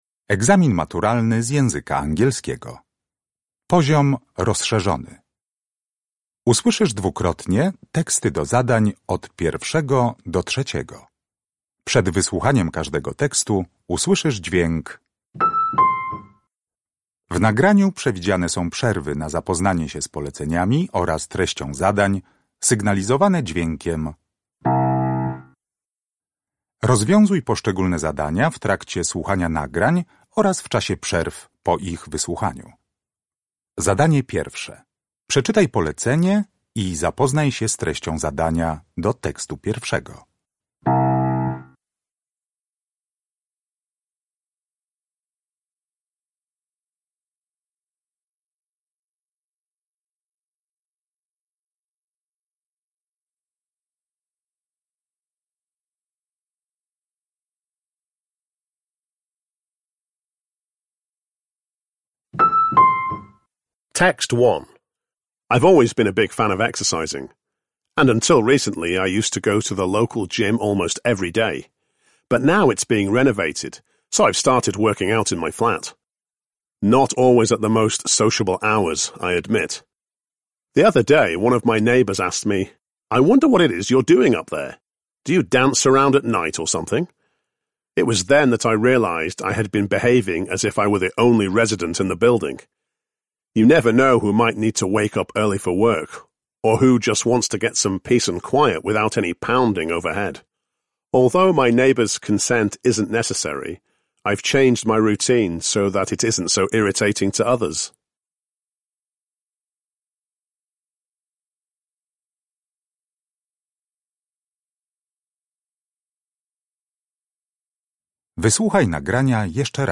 (0–4) Uruchamiając odtwarzacz z oryginalnym nagraniem CKE usłyszysz dwukrotnie wypowiedź organizatora letniego obozu dla młodzieży.
(0–5) Uruchamiając odtwarzacz z oryginalnym nagraniem CKE usłyszysz dwukrotnie pięć wypowiedzi na temat pomników.